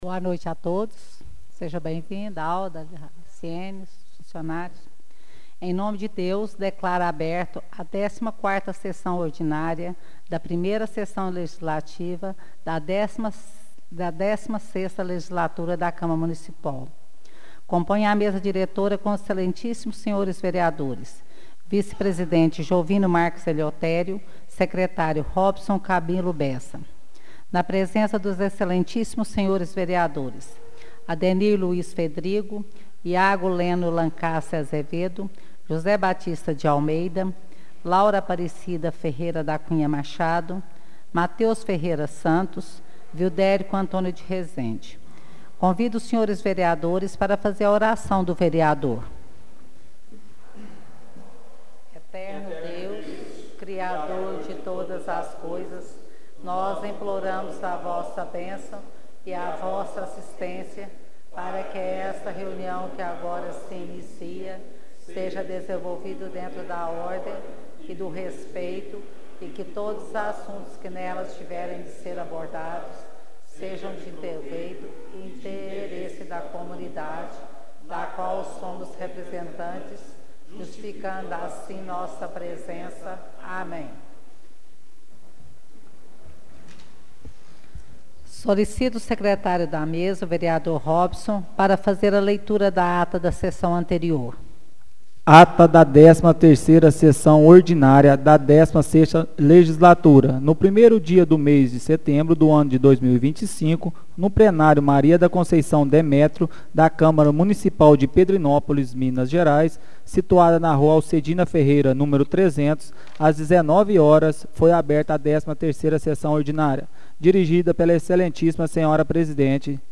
Áudio da 14ª Sessão Ordinária de 2025 — Câmara Municipal de Pedrinópolis